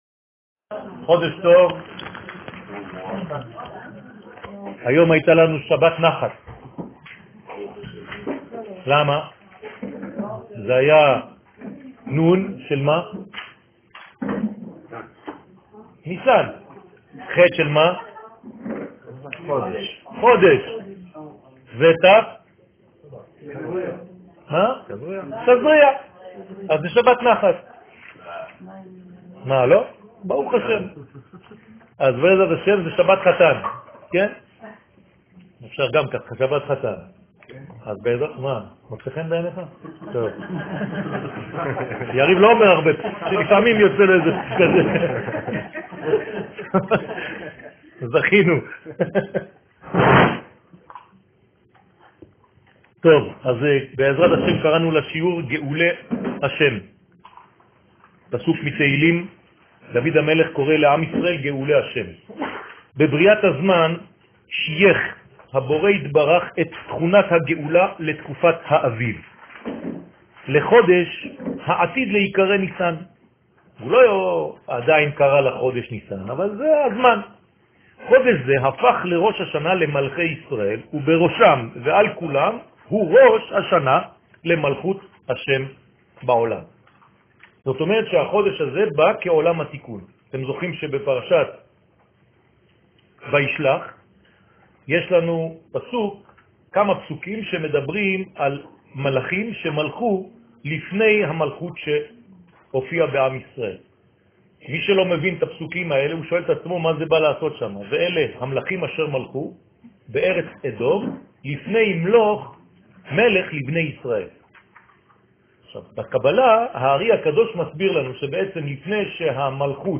שיעור ראש חודש ניסן תשע''ו